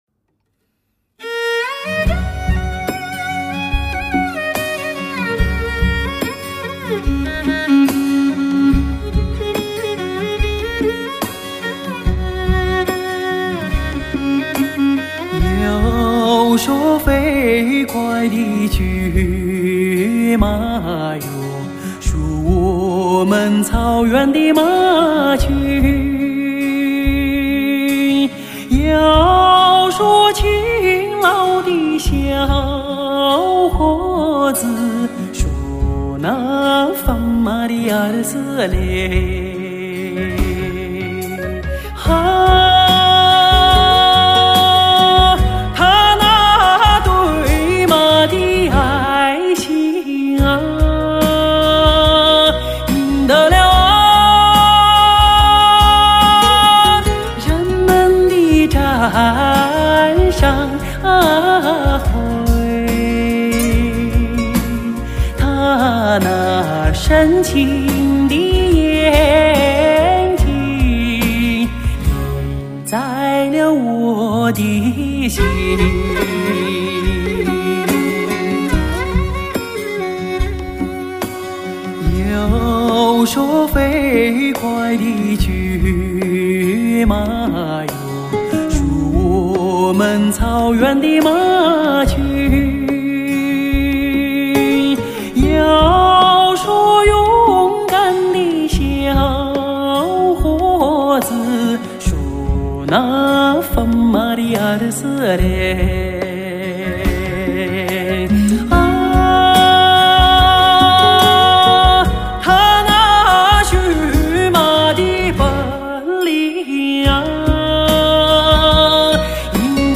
浑厚深沉的女中音，轻轻地和着悠扬宽广的蒙古长调，犹如，一条条蜿蜒九曲的“母亲的河”，在蓝色的蒙古高原上静静地流淌着。